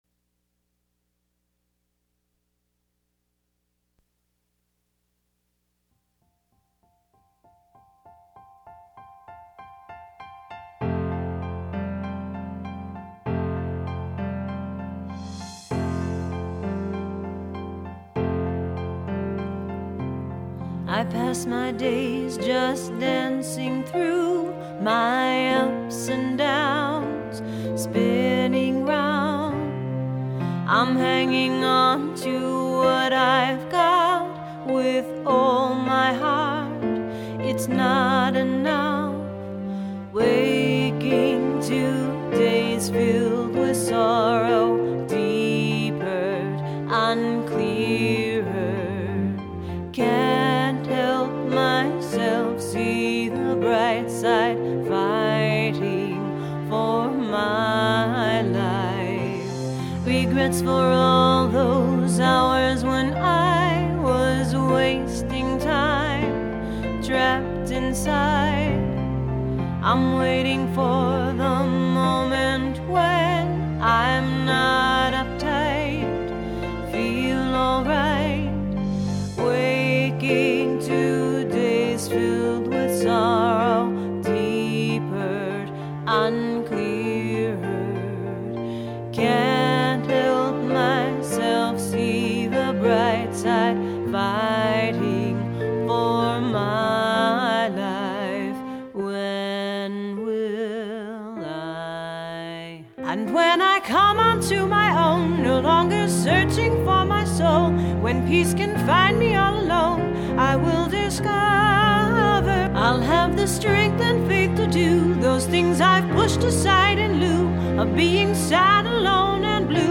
inspirational music to uplift your spiritsWell, half a lifetime and my spiritual awakening is happening! I finally answered my question from those many years ago, “When Will I Be?”
The wonderful vocals